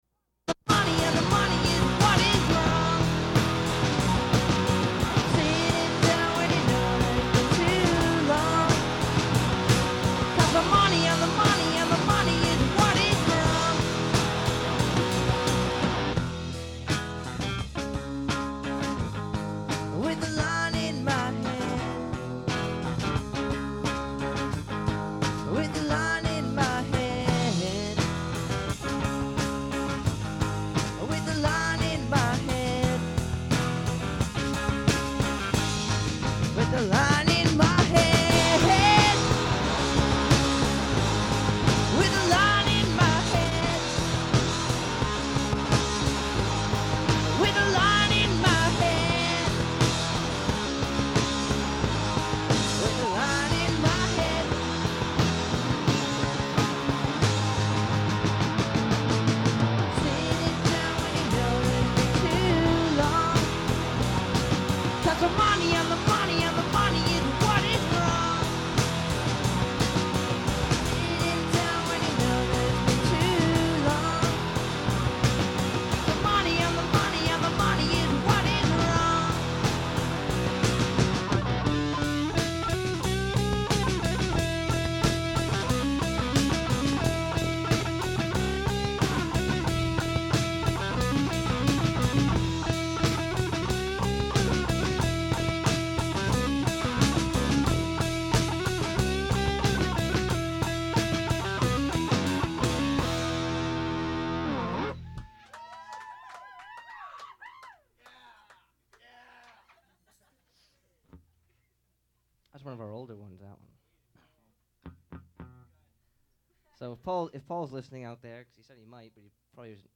Recording of a live performance